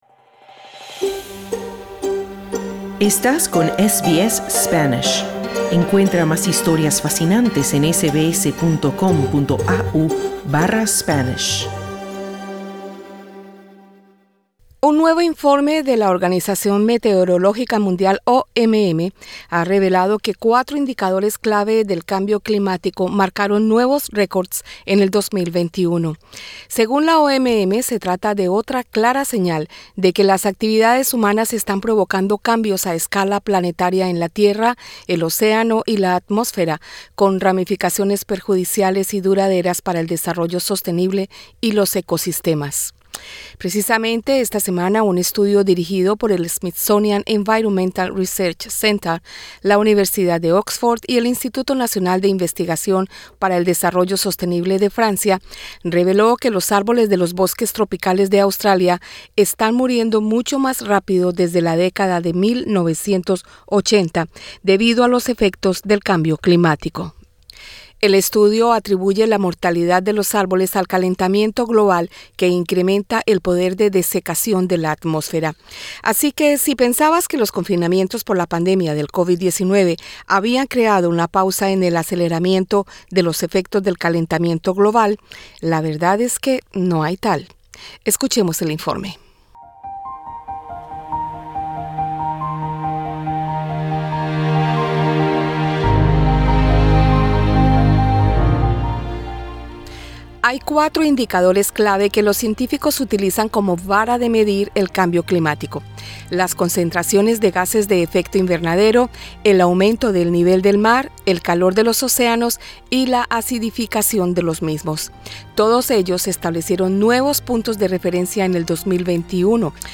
Entrevista con la perita en manejo ambiental y desarrollo